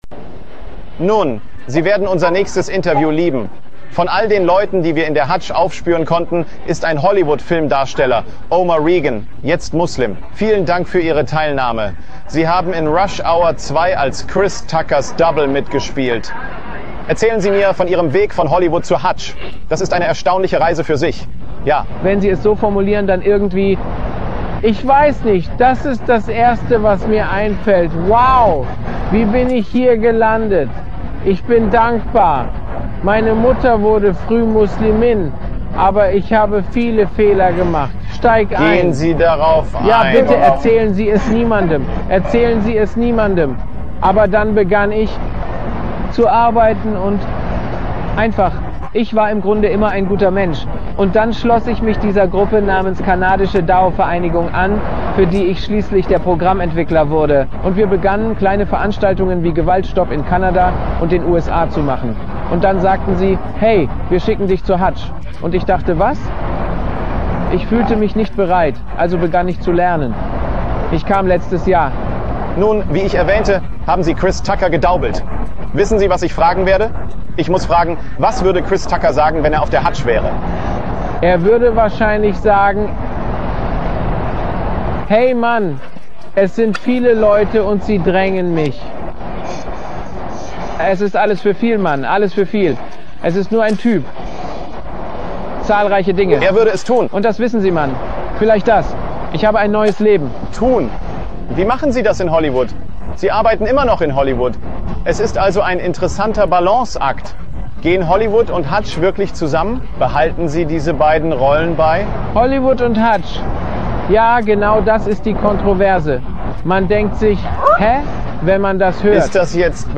wird vom internationalen Fernsehsender Al-Jazeera interviewt